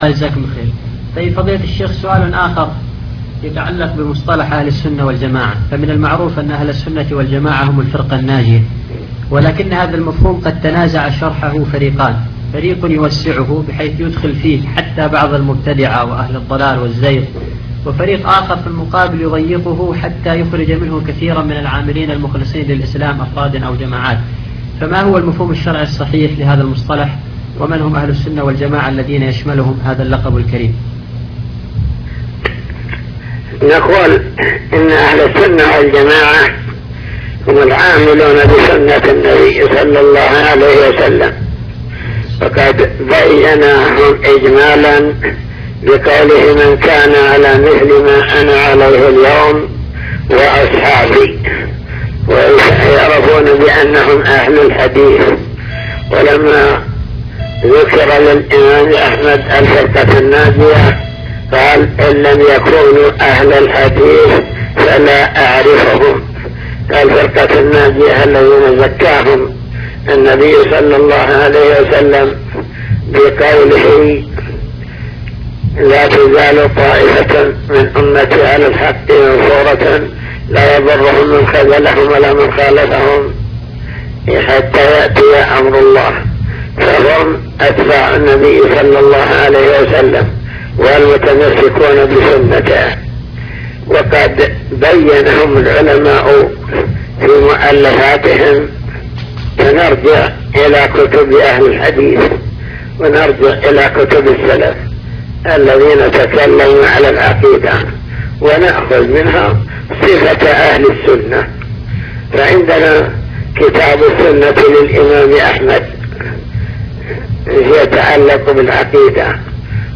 لقاءات